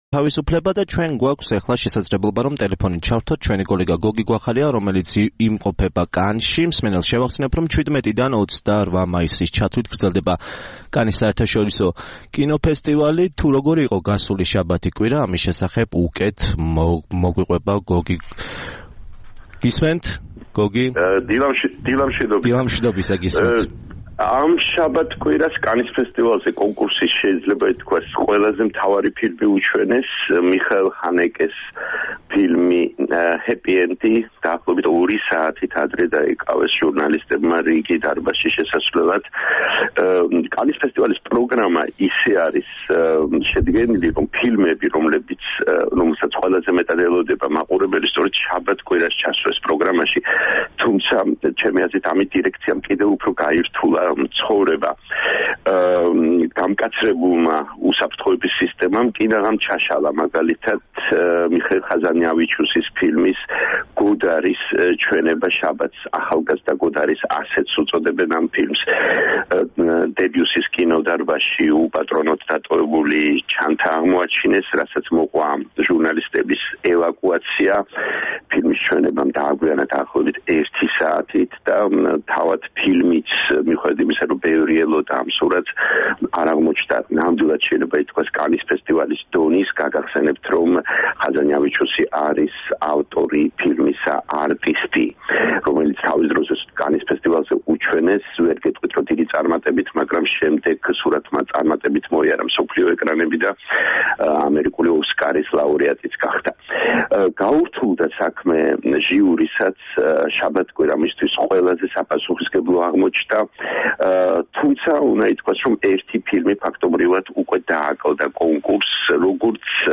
რეპორტაჟი კანის კინოფესტივალიდან